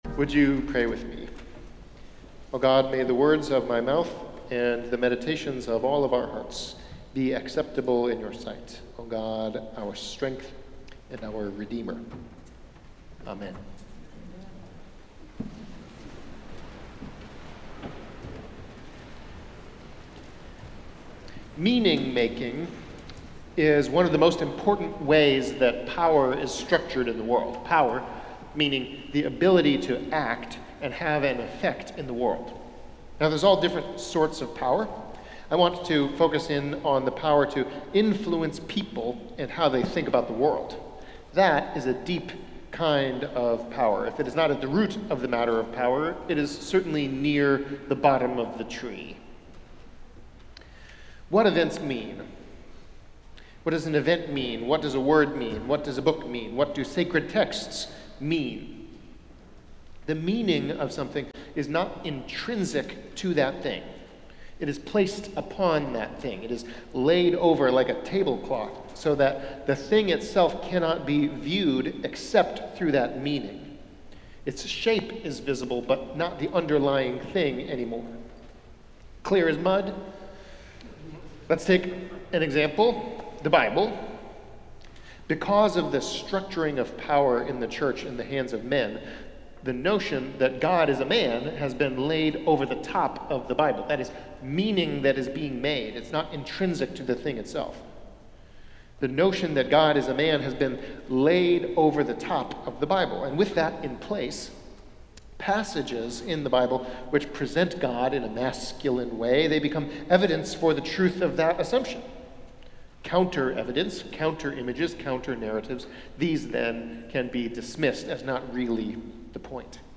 Festival Worship - Phillis Wheatley Sunday 2024
Phillis Wheatley Sunday sermon.mp3